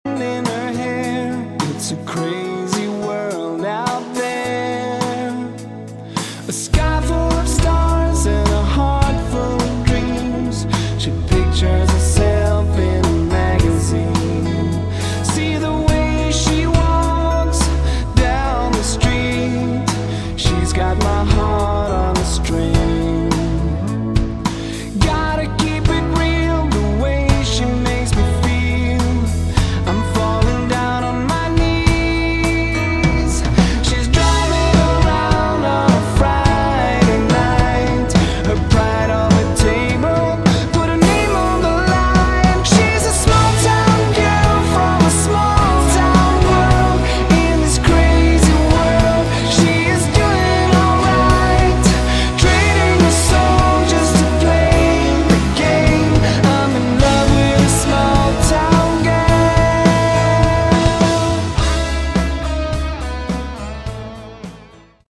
Category: Scandi AOR
keyboards
guitars
vocals
drums